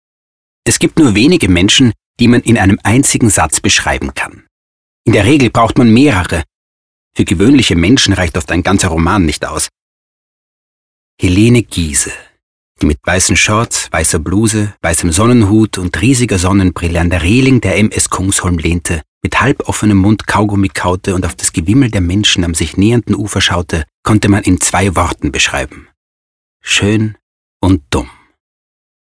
wienerisch
Sprechprobe: Sonstiges (Muttersprache):